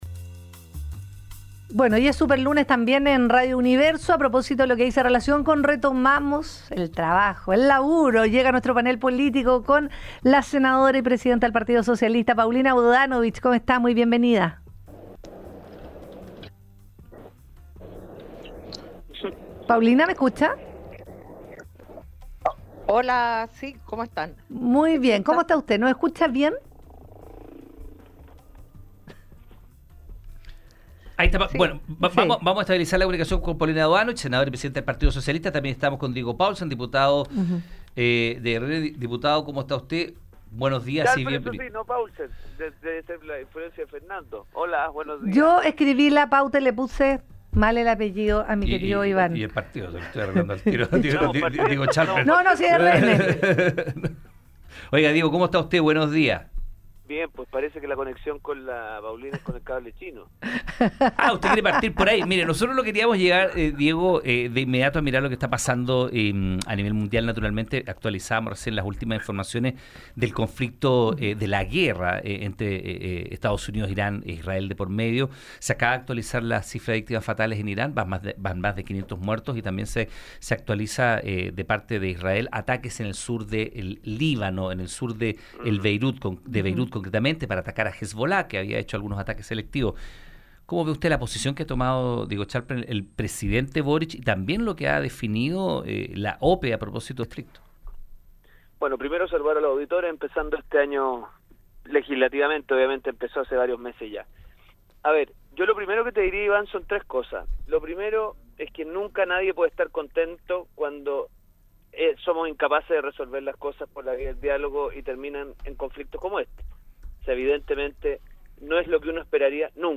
En el marco del “Súper Lunes” de retorno a las funciones legislativas, el panel político de Universo al Día abordó la crisis diplomática y técnica generada por el proyecto de cable submarino hacia China.
El diputado Diego Schalper fue enfático en cuestionar las explicaciones oficiales sobre la anulación de un decreto administrativo relacionado con el cable.